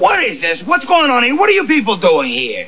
Male Voice -